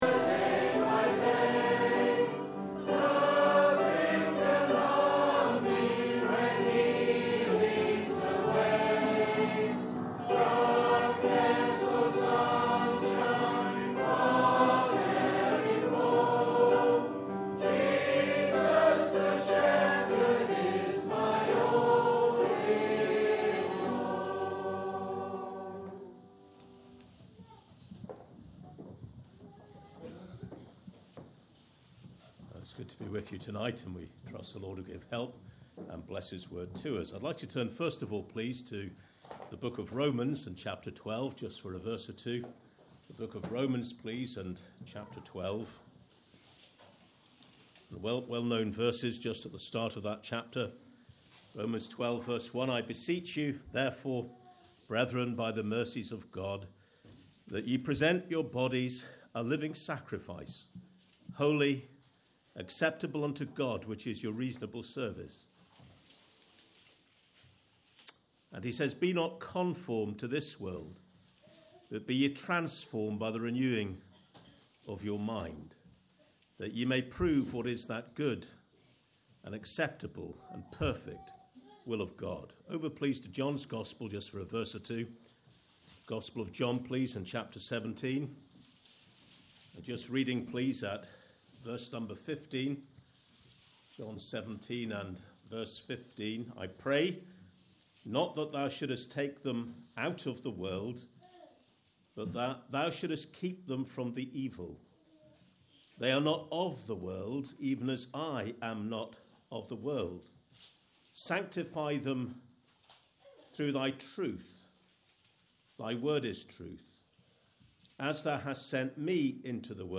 A recording of our Regular Saturday Night Ministry Meeting.